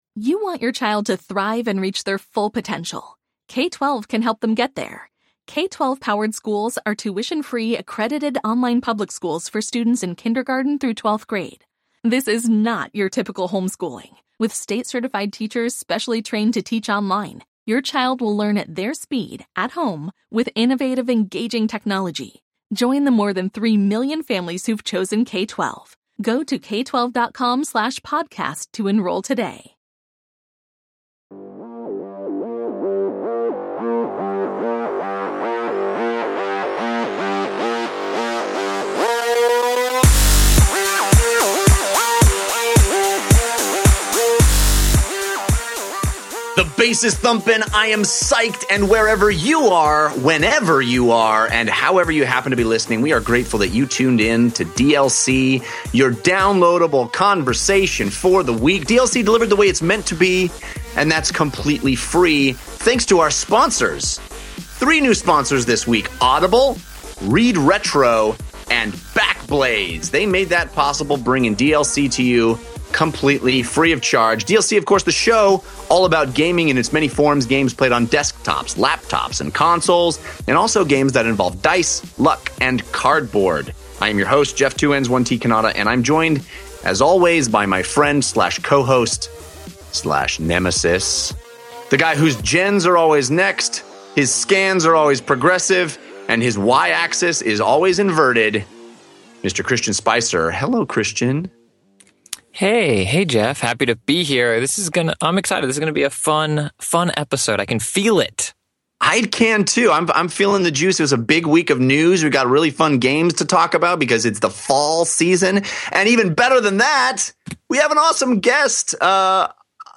All that, plus YOUR phone calls!